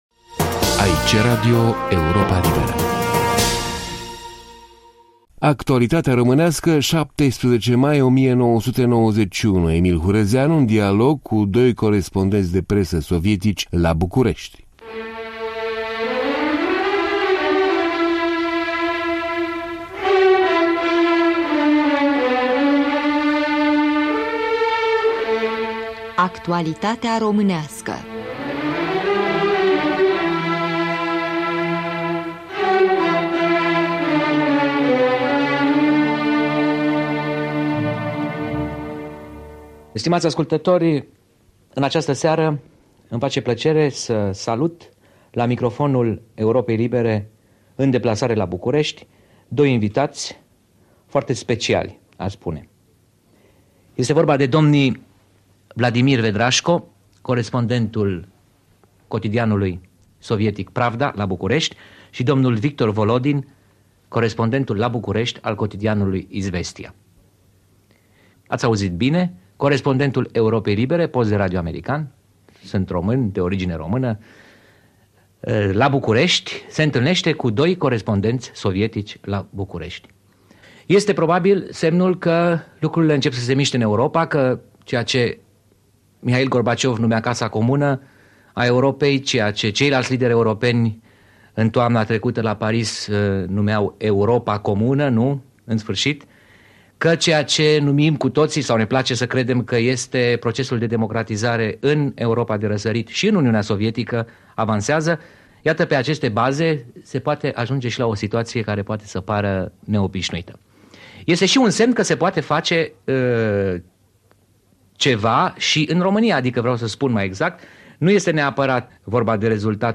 o discuție